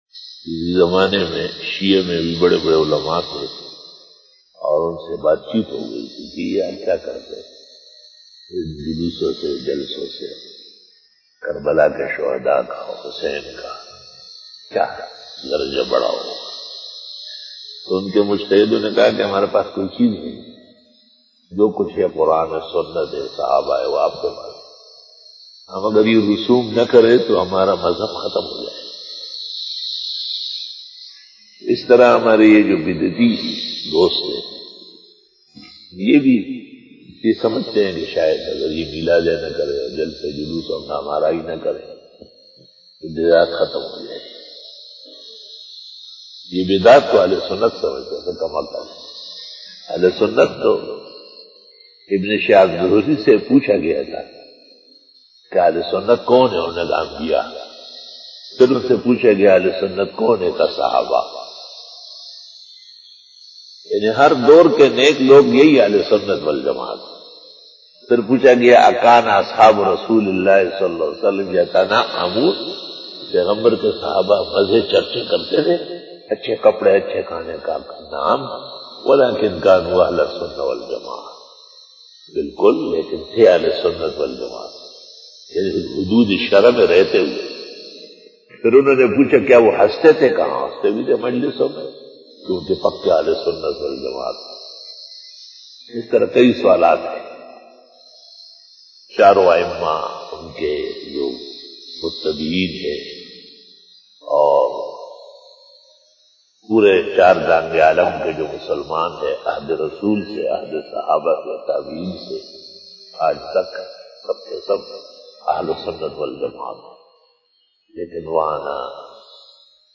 Fajar bayan
بیان بعد نماز فجر بروز جمعہ